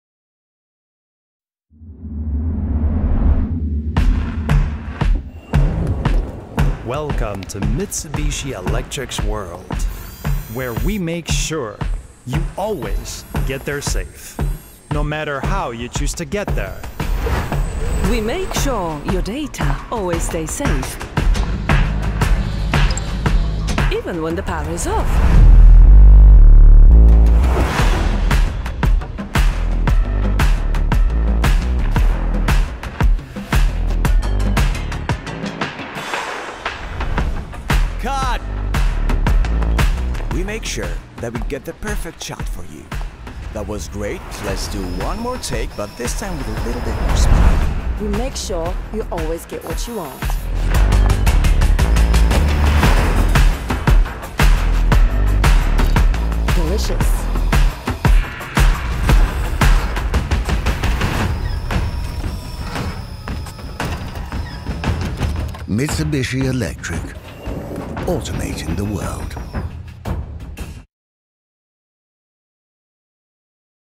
Female
Adult (30-50)
Deep, Jazzy, warm, rich alt
Radio Commercials